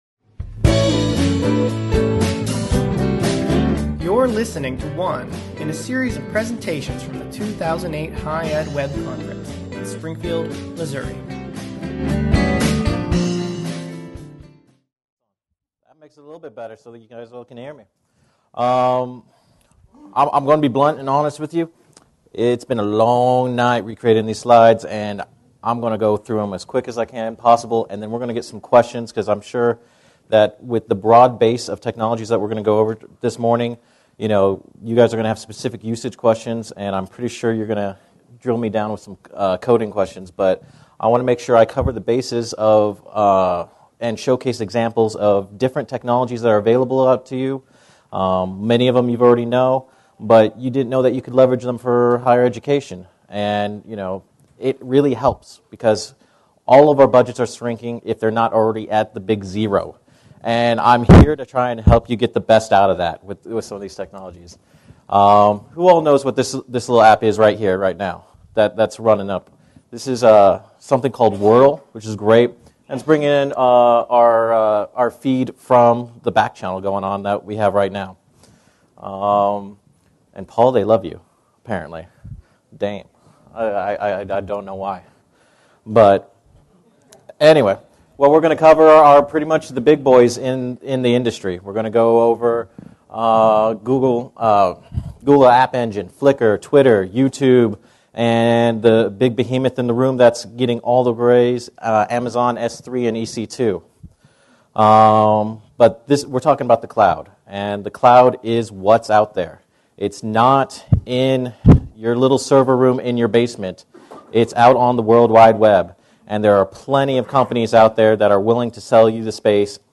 Session Details - HighEdWeb 2008 Conference: Infinite Solutions